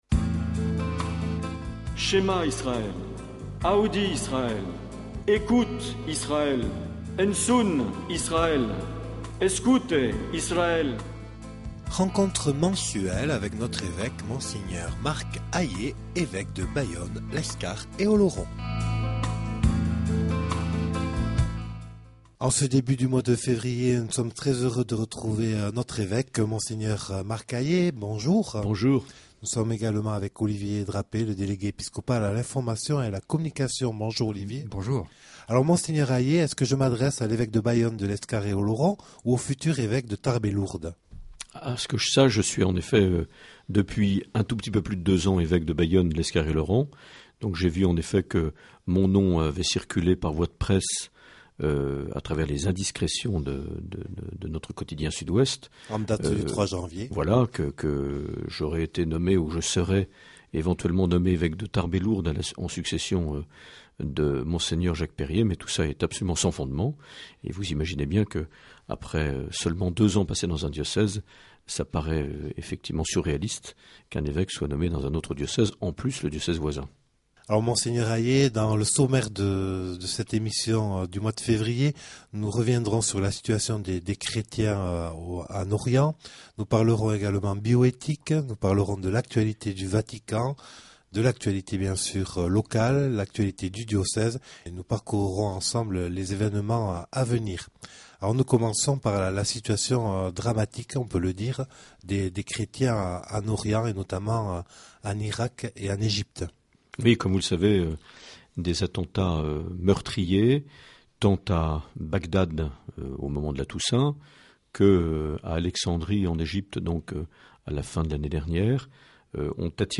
Les entretiens
Une émission présentée par Monseigneur Marc Aillet